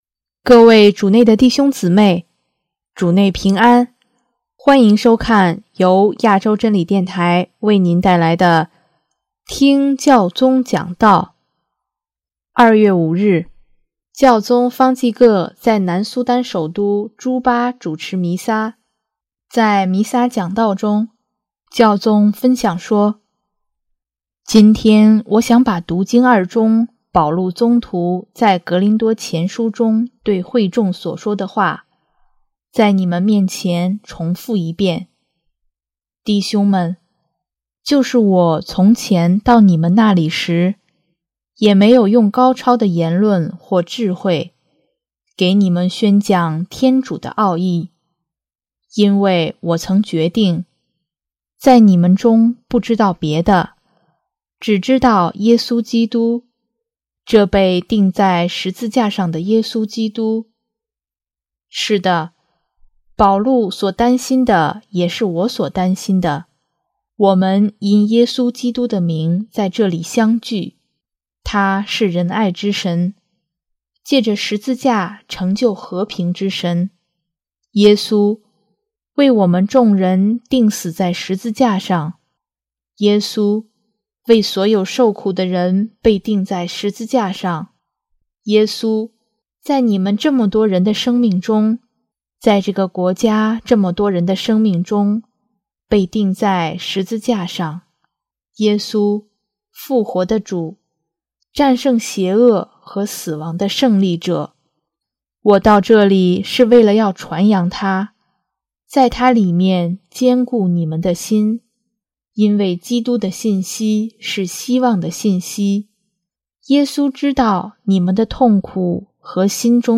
【听教宗讲道】|“你们是地上的盐......世界的光.....”
2月5日，教宗方济各在南苏丹首都朱巴主持弥撒，在弥撒讲道中，分享说：